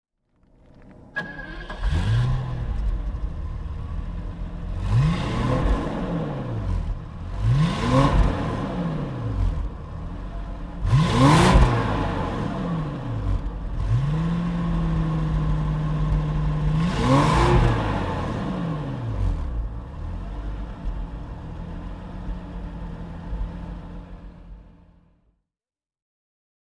Jaguar XJ-S H.E. (1986) - Starten und Leerlauf
Jaguar_XJ-S_1988.mp3